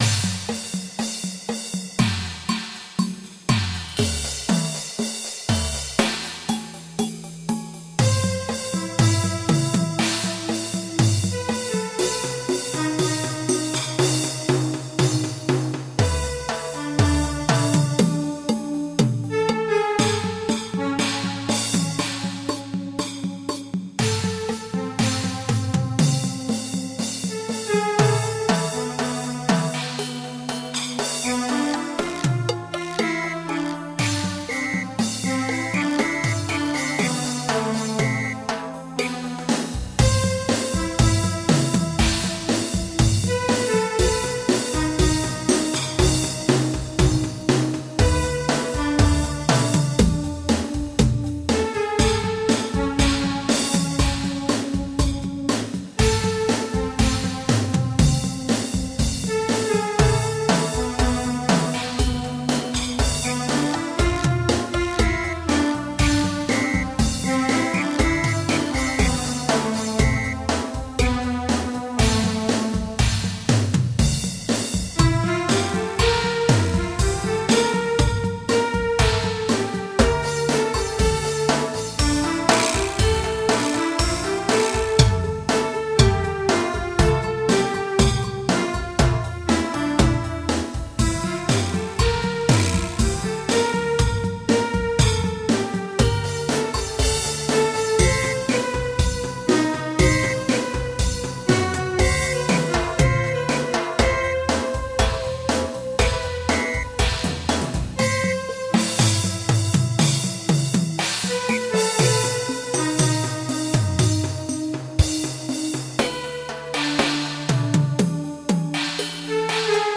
Мелодия